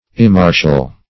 Immartial \Im*mar"tial\, a. Not martial; unwarlike.